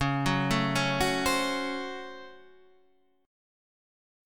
C# Major 7th Suspended 4th Sharp 5th